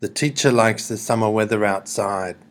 theteacherlikesFAST.mp3